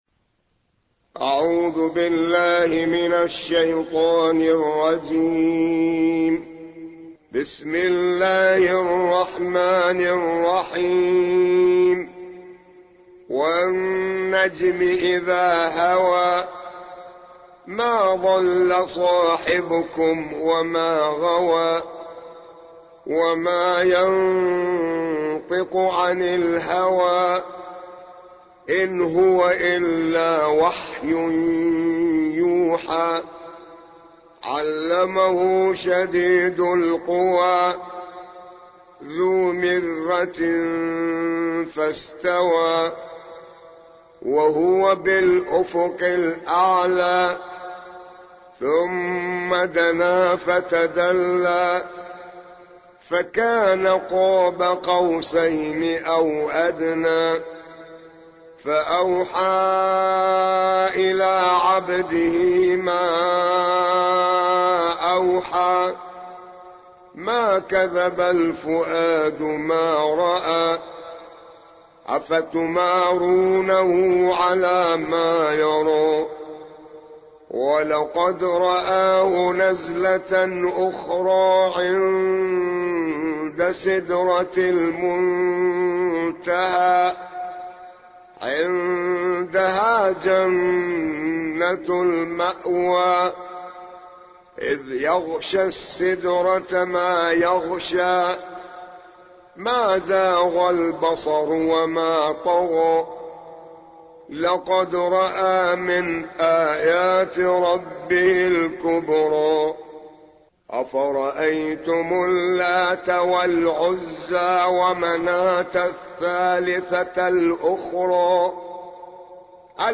53. سورة النجم / القارئ